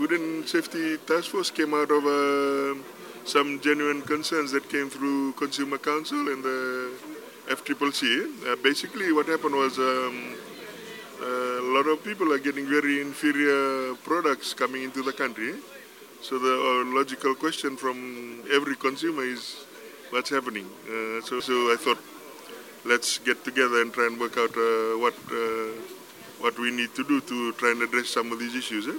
Kamikamica adds that regular updates from the Consumer Council and FCCC prompted the decision to address the issue through the establishment of the task force.